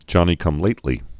(jŏnē-kŭm-lātlē)